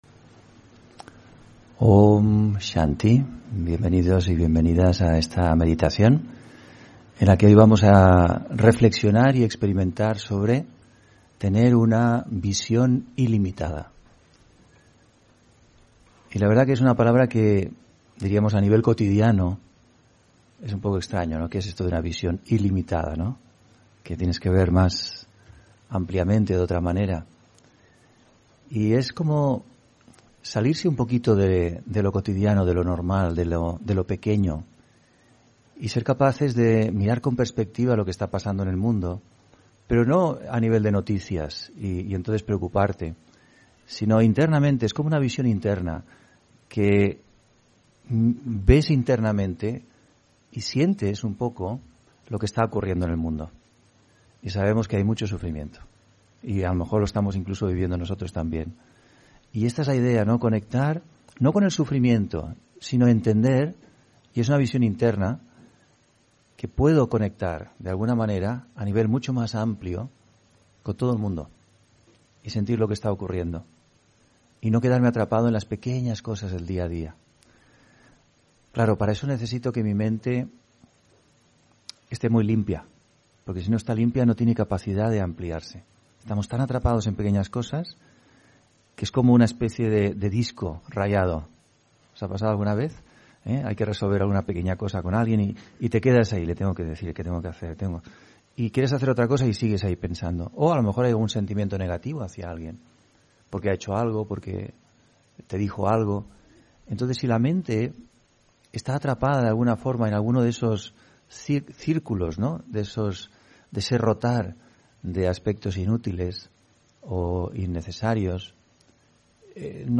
Meditación y conferencia: Una visión ilimitada (2 Septiembre 2022)